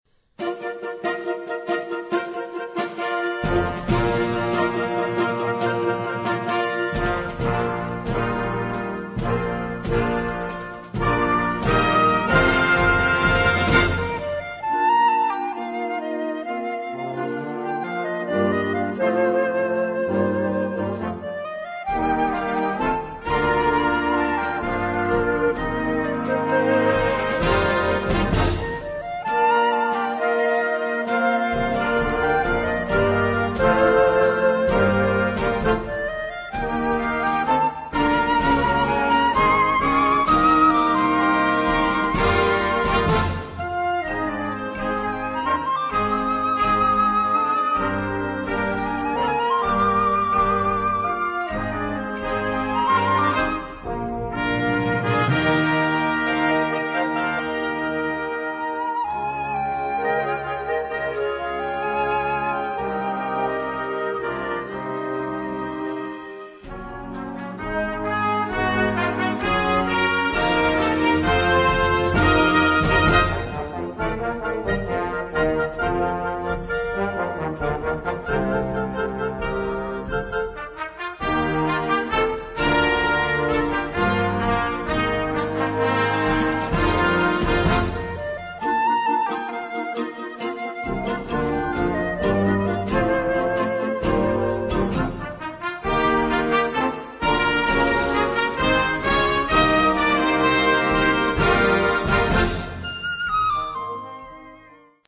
Solo for Bb/Eb/C (BC) and band.
Noten für Jugendblasorchester.